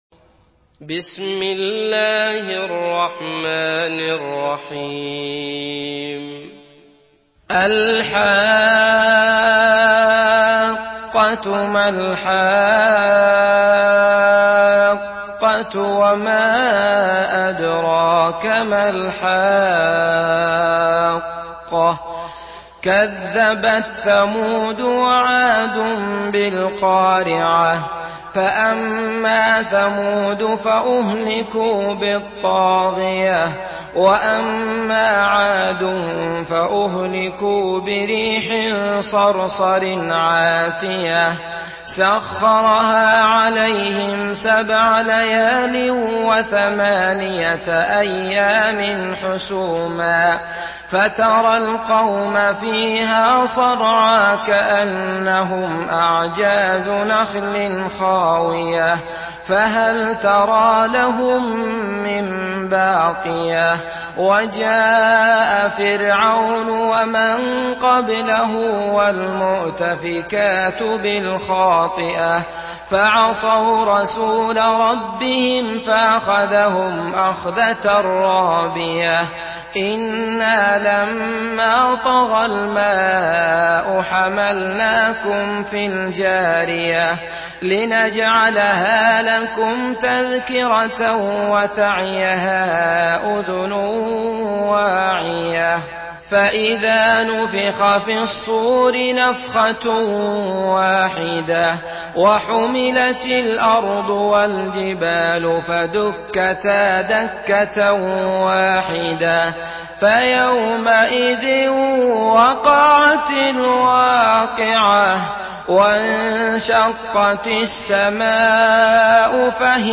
Surah Al-Haqqah MP3 by Abdullah Al Matrood in Hafs An Asim narration.
Murattal Hafs An Asim